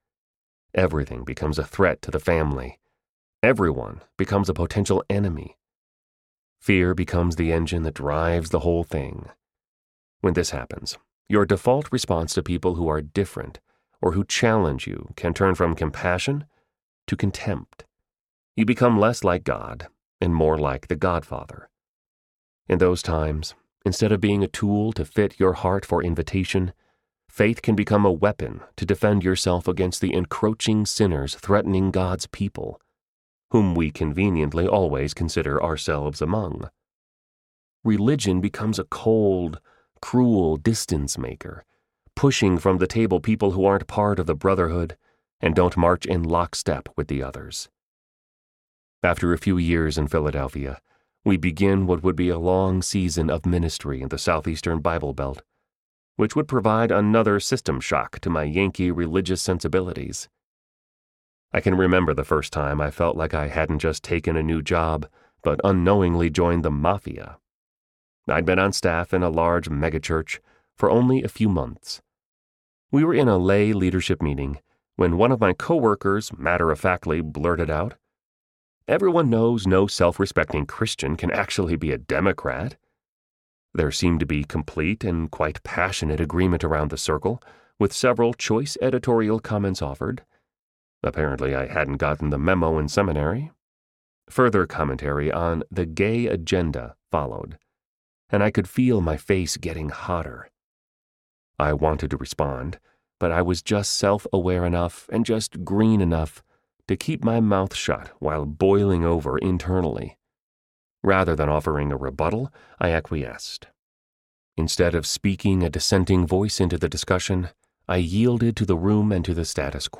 A Bigger Table Audiobook
Narrator
6.53 Hrs. – Unabridged